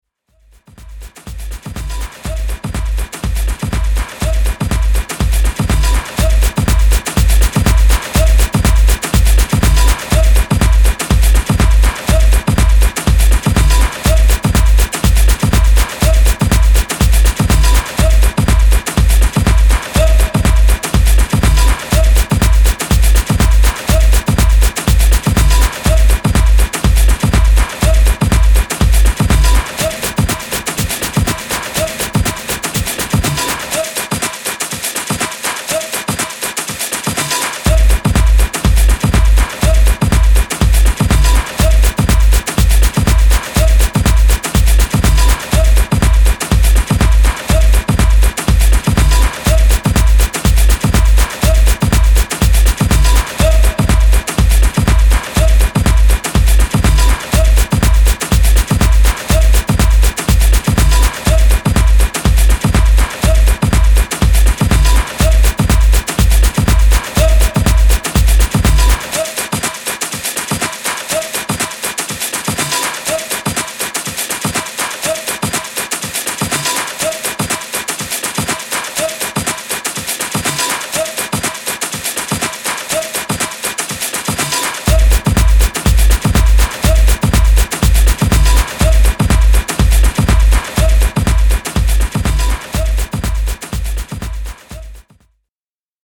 122 BPM